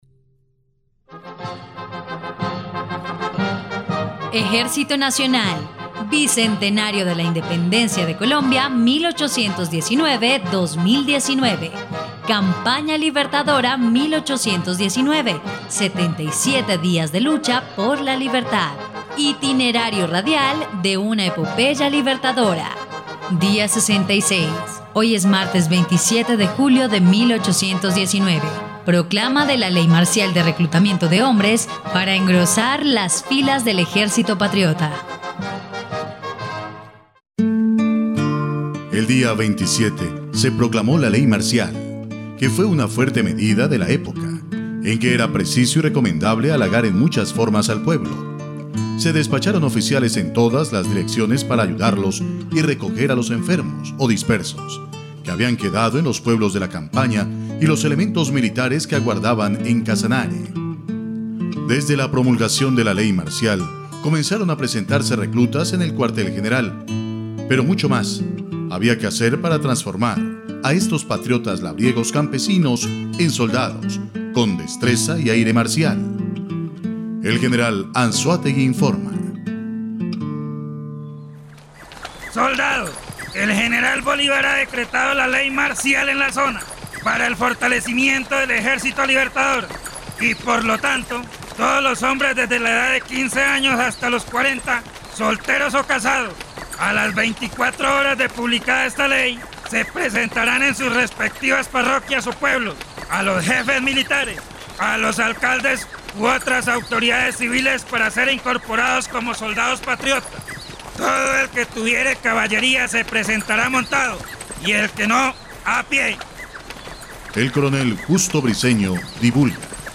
dia_66_radionovela_campana_libertadora.mp3